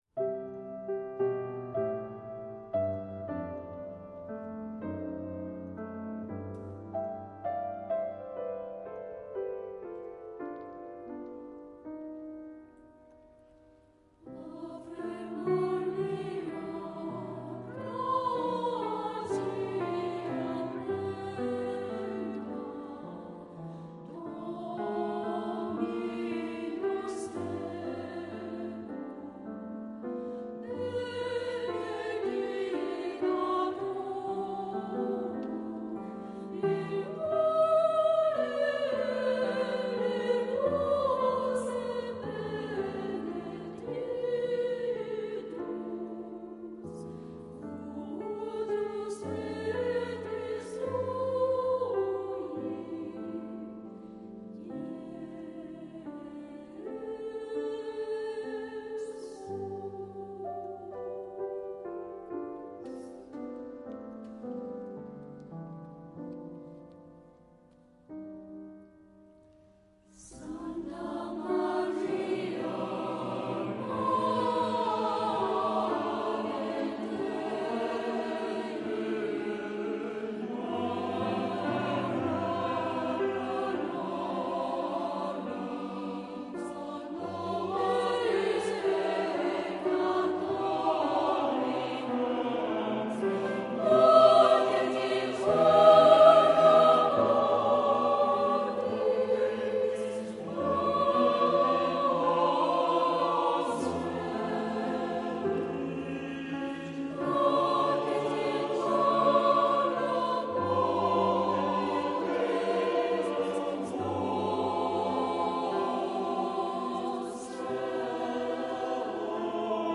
除定時練習外，每年也騁請聲樂 家來團作培訓，是本澳一支充滿活力的 合唱團。